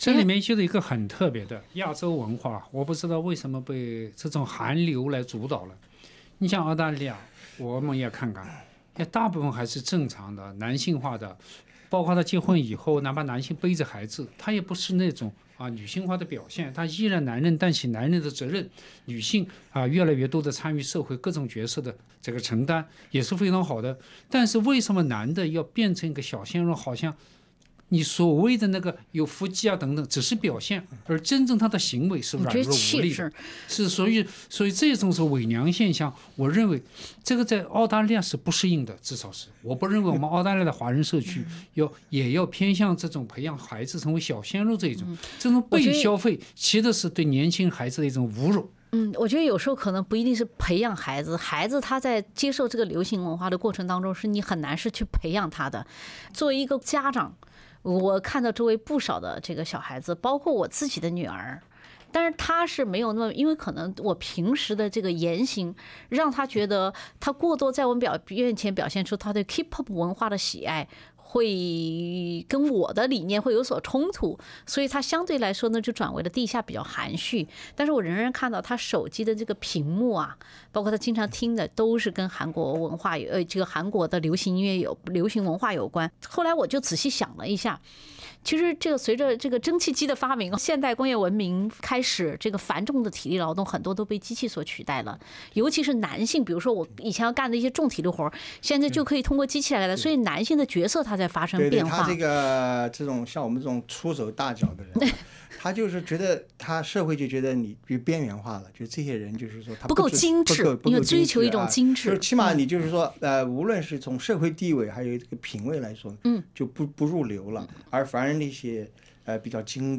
欢迎收听SBS 文化时评栏目《文化苦丁茶》，本期话题是：男色时代（第二集）-长得好看也是一种实力？